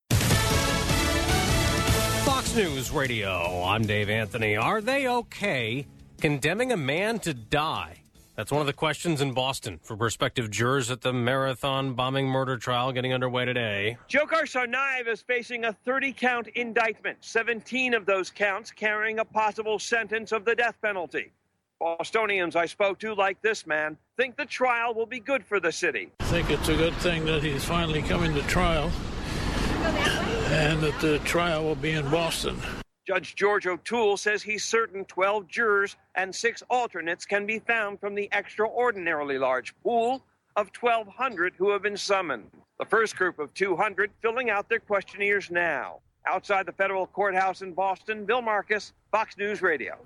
10am-almost-live.mp3